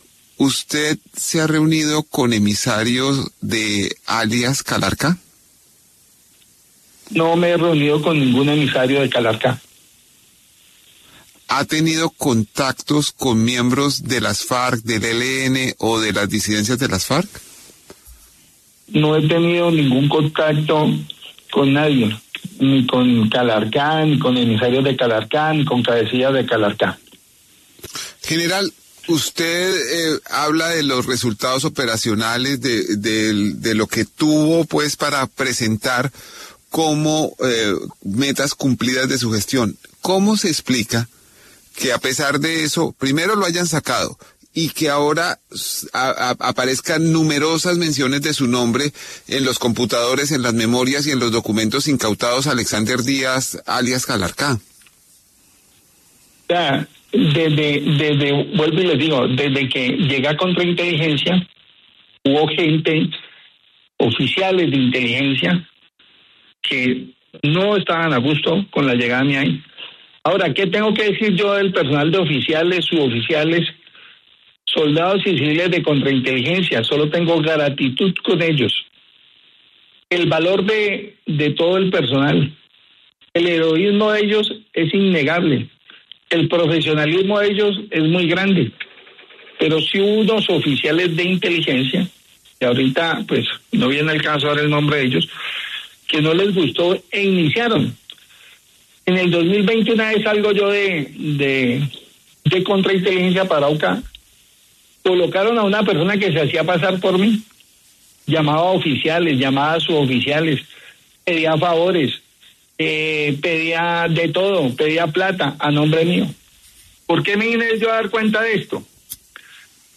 El general Juan Miguel Huertas habló en exclusiva para El Reporte Coronell, de La W, sobre su presunta relación con las disidencias de las Farc de alias ‘Calarcá’ y dijo que, presuntamente, lo estaban suplantando.